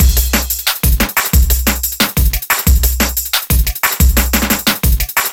第8节 Dnb循环180bpm
Tag: 180 bpm Drum And Bass Loops Drum Loops 918.79 KB wav Key : Unknown